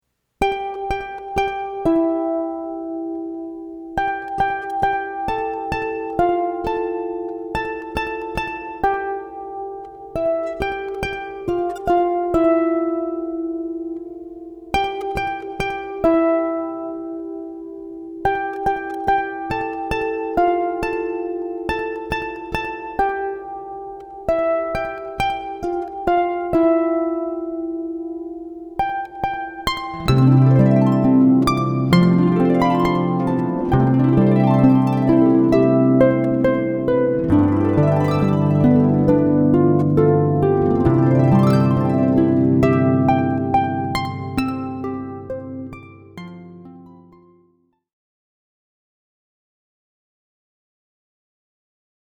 Harp Music Samples, Recorded Live